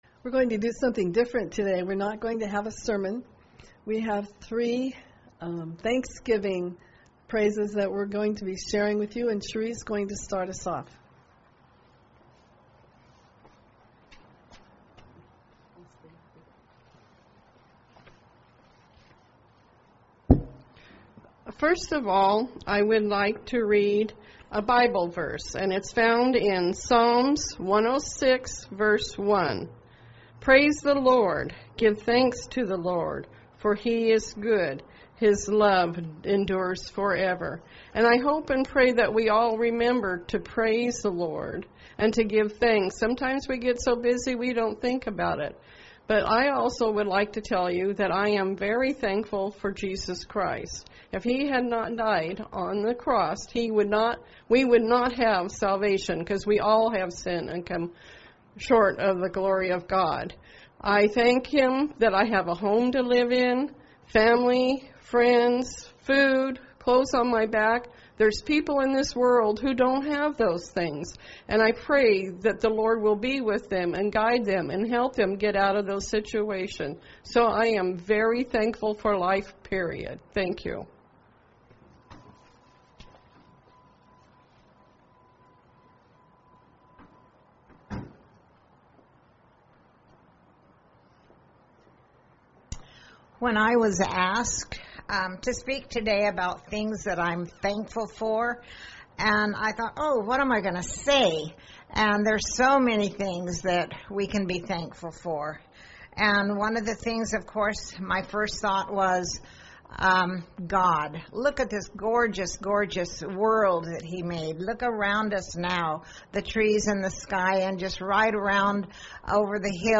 Sermons-Misc.
Date Memorial Presenter 11-29-20.mp3 Testimonies Church members • CLICK HERE TO GO BACK